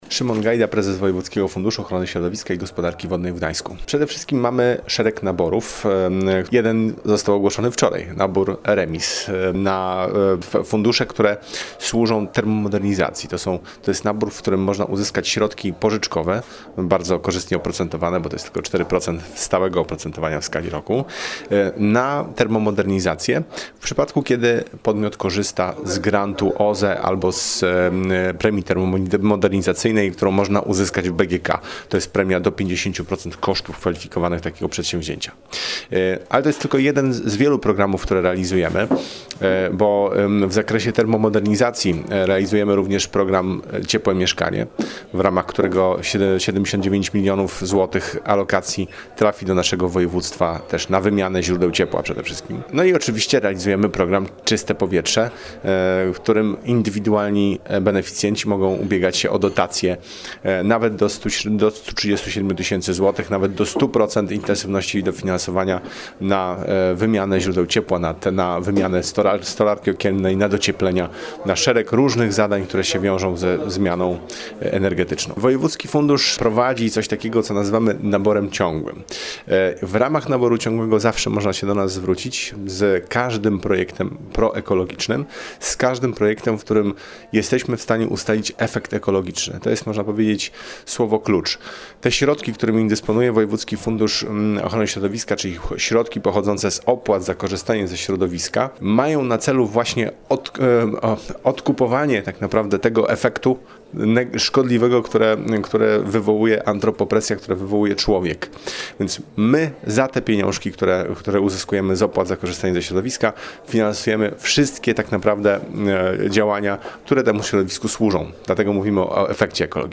POROZMAWIAJMY O FUNDUSZACH – KONFERENCJA W KOCZALE
Spotkanie odbyło się wczoraj (27.04) w sali widowiskowej Gminnego Ośrodka Kultury.